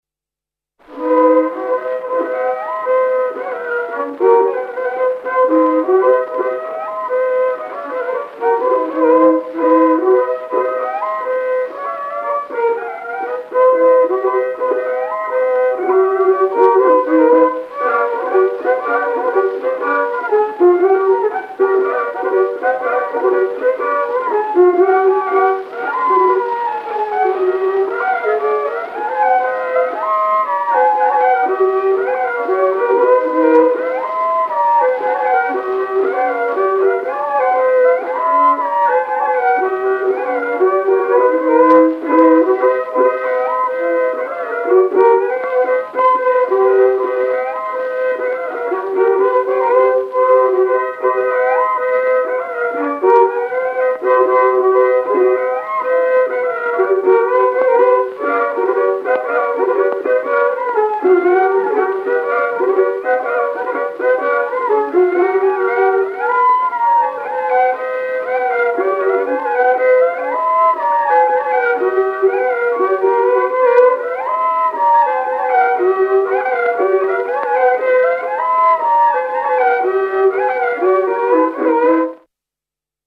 II viiul